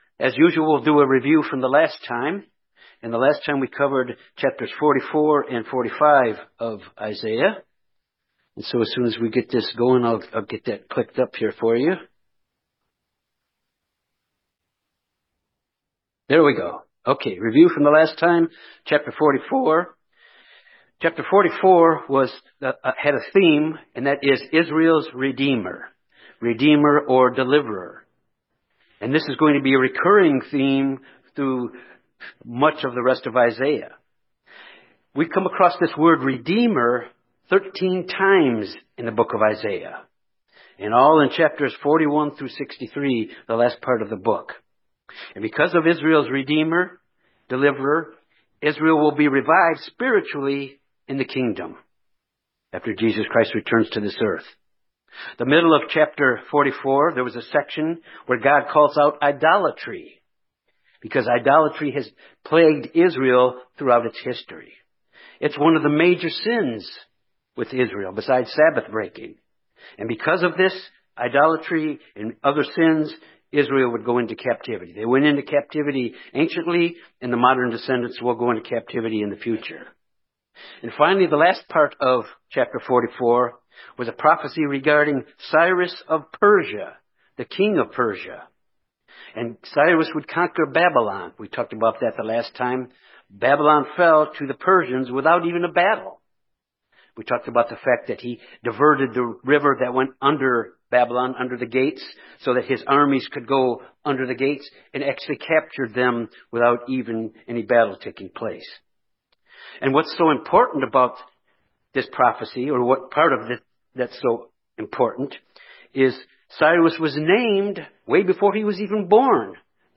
This message examines chapters 46-48 of the book of Isaiah.